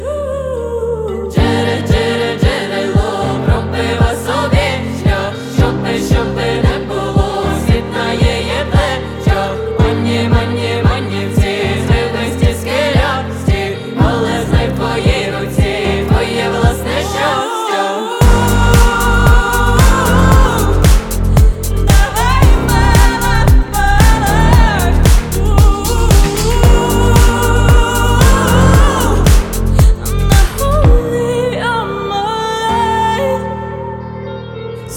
Жанр: Украинские
# Поп